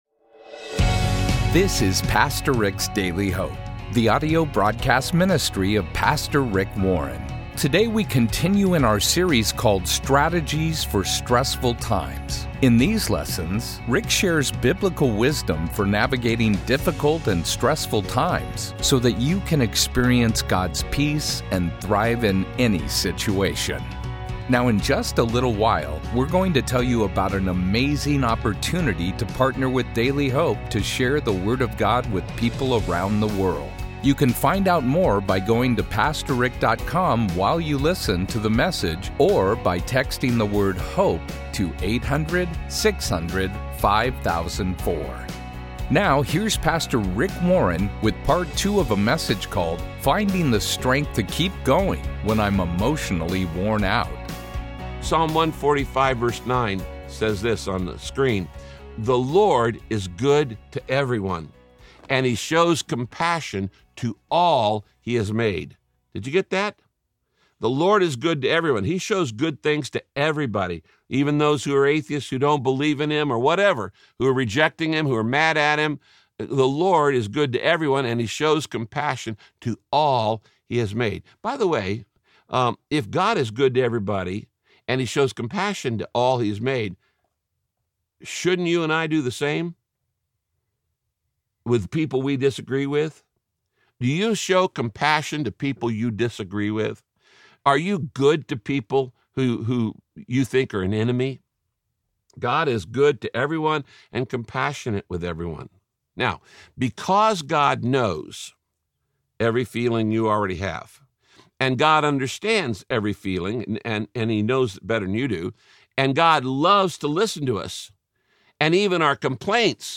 In this broadcast, Pastor Rick encourages you to dump everything you’re feeling right now onto the Lord—he wants to hear it all!
Radio Broadcast Finding the Strength to Keep Going When I’m Emotionally Worn Out – Part 2 Do you feel under attack?